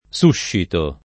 suscito [ S2 ššito ]